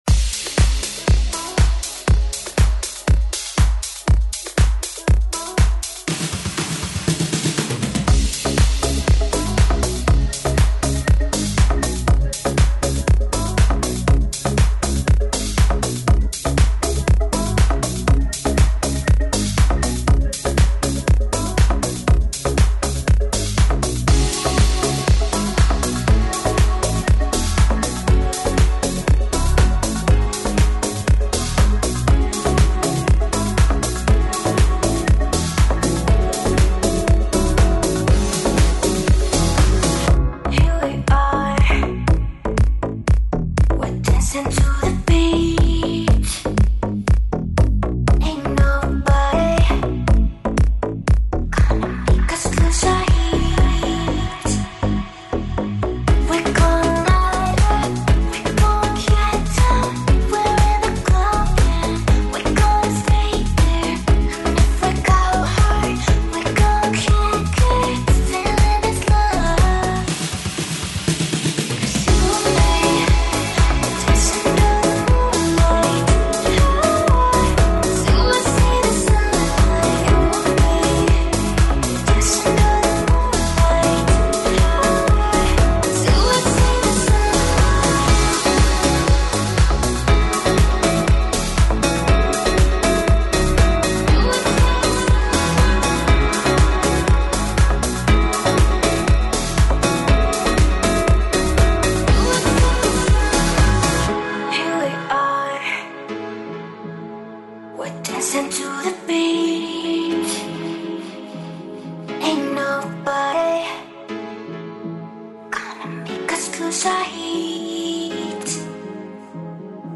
Dance-Eletronicas